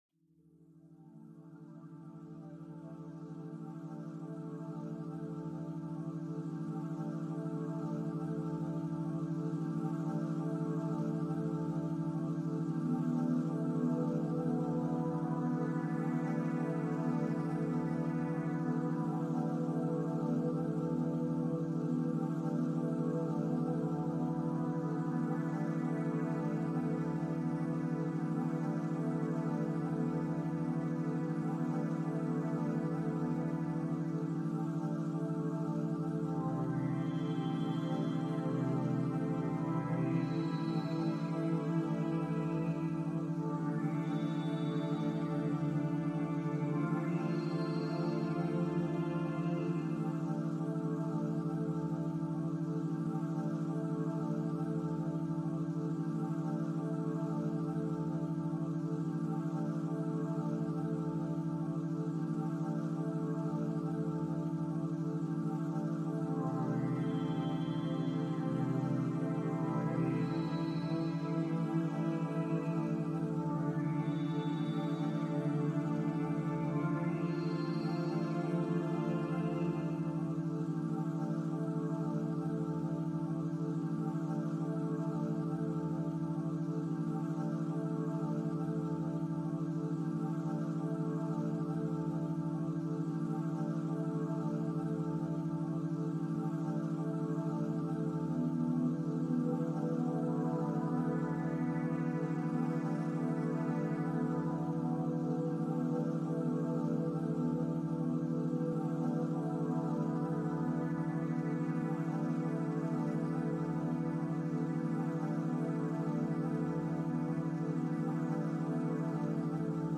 Pluie calme essentielle · méthode nocturne éprouvée pour travail profond